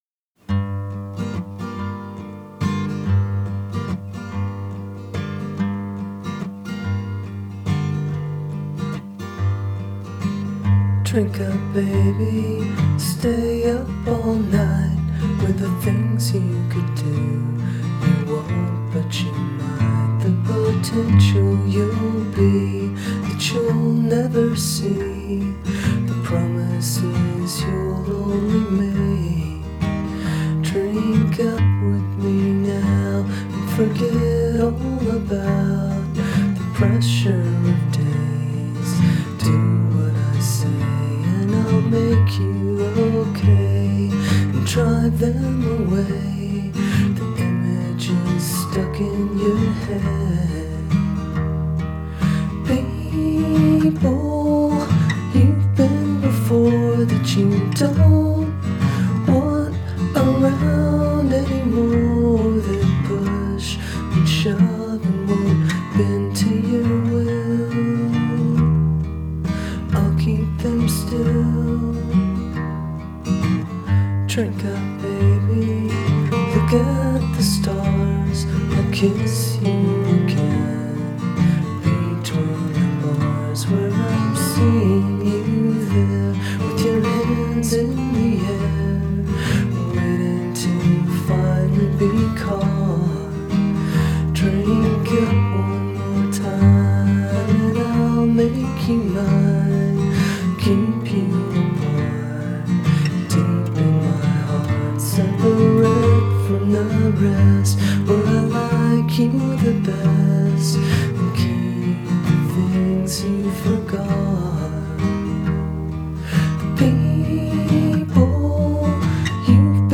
Genre: Indie Rock / Singer-Songwriter / Acoustic